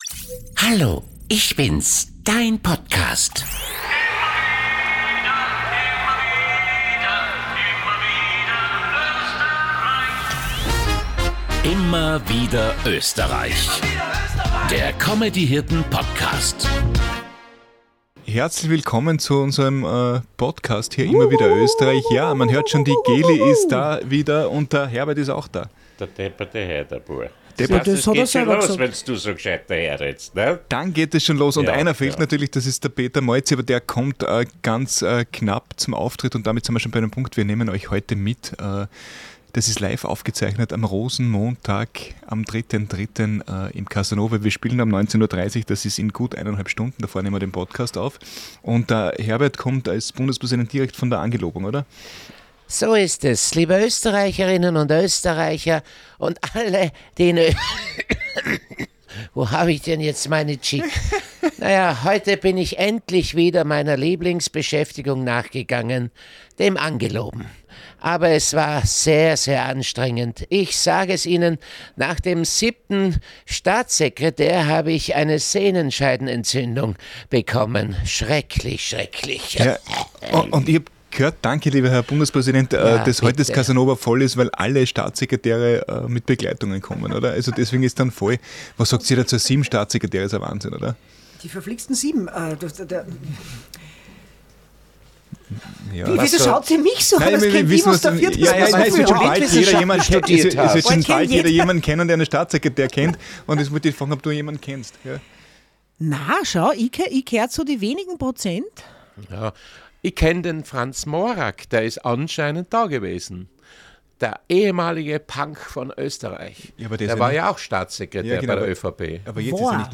Die Comedy Hirten haben am vergangenen Montag nicht nur einen Auftritt im Wiener Casanova gehabt, sondern davor backstage auch einen Podcast aufgenommen, um über Rituale und Lampenfieber vor der Show zu sprechen.
Aber die anderen 3 sprechen darüber.